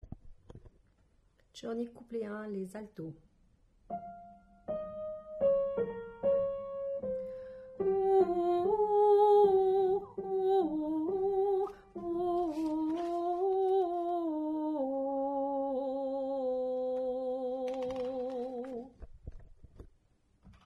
Alto
coernij1_Alto.mp3